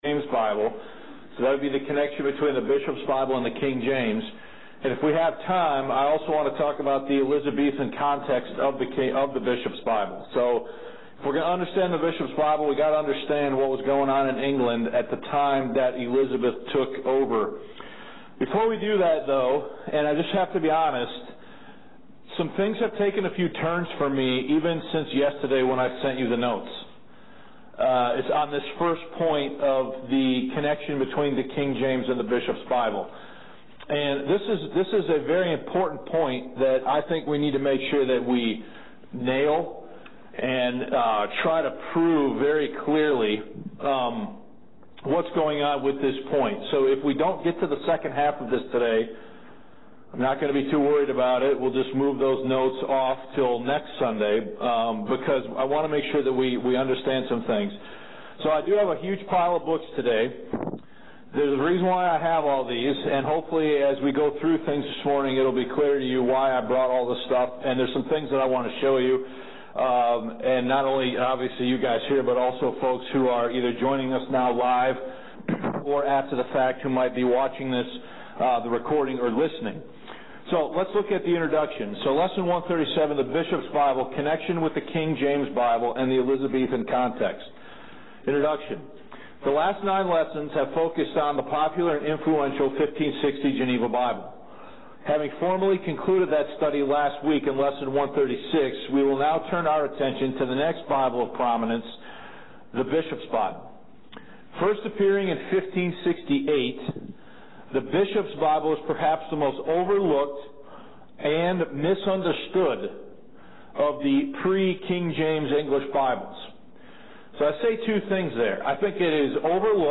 These notes have been revised twice since this lesson was taught on Sunday, February 21, 2021, at Grace Life Bible Church in Grand Rapids, MI.